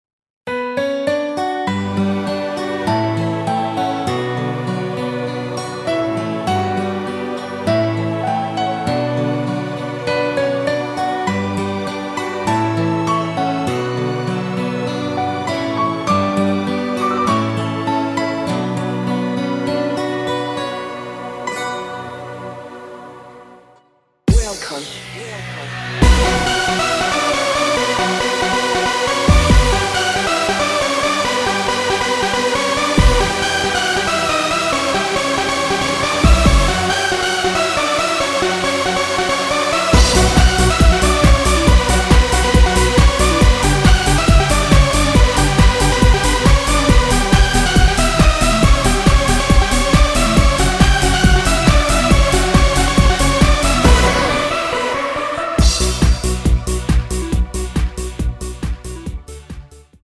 (-1) 내린 MR 입니다.(미리듣기 참조)
Bm
◈ 곡명 옆 (-1)은 반음 내림, (+1)은 반음 올림 입니다.
앞부분30초, 뒷부분30초씩 편집해서 올려 드리고 있습니다.